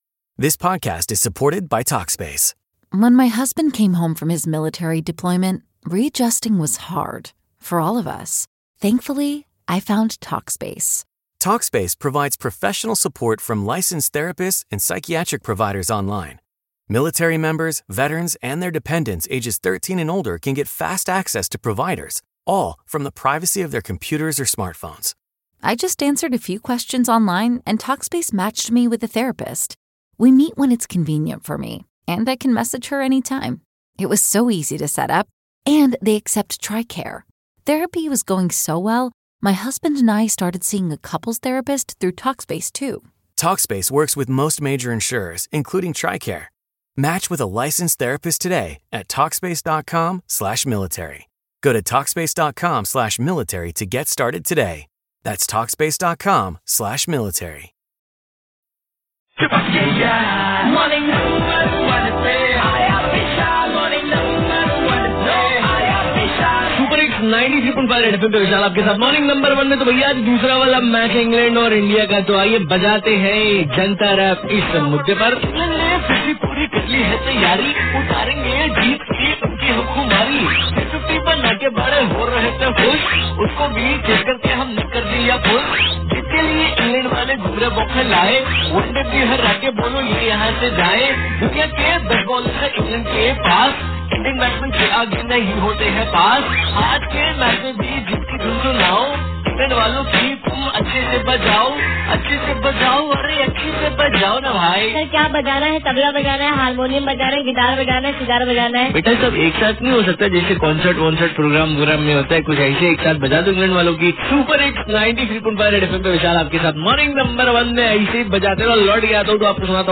JANTA RAP FOR TODAY'S MATCH :)